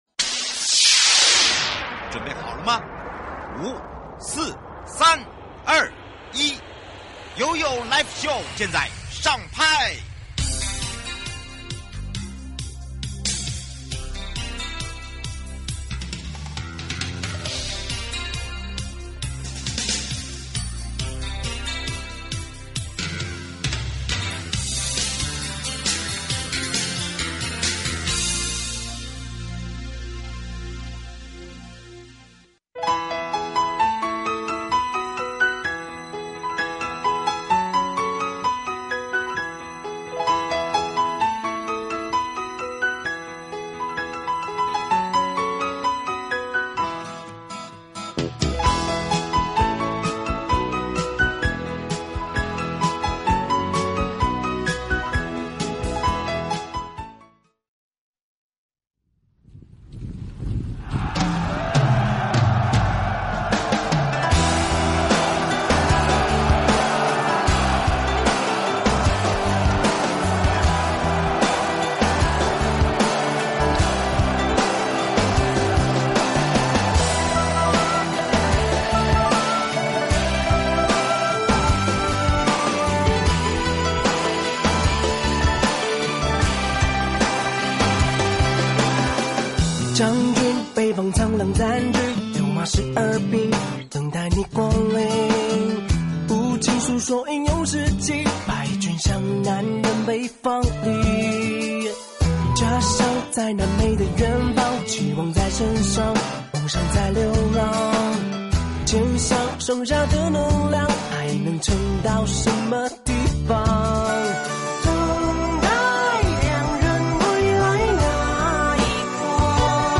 節目內容： 幸福北海岸北區觀光區市集日本周五登場啦~~快來找我們合體喔!!不見不散~~好康大放送!! 受訪者：